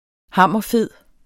Udtale [ ˈhɑmˀʌˈ- ]